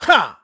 KaselB-Vox_Attack1_kr_b.wav